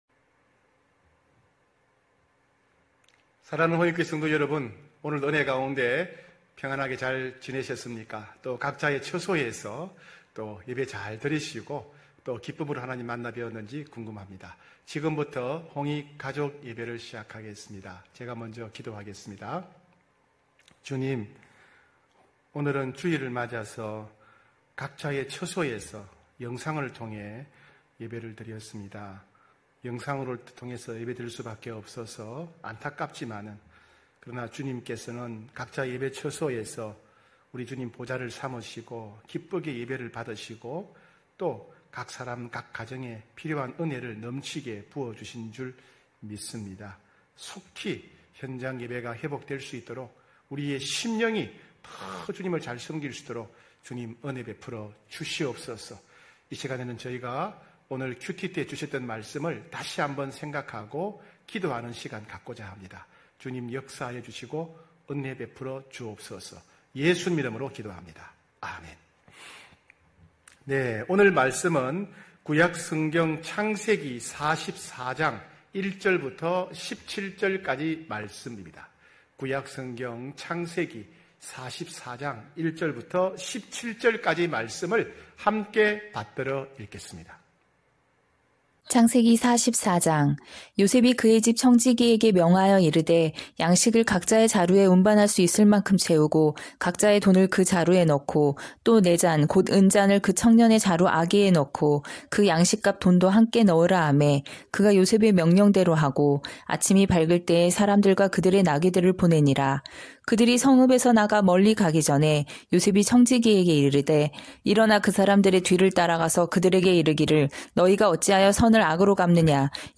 9시홍익가족예배(9월13일).mp3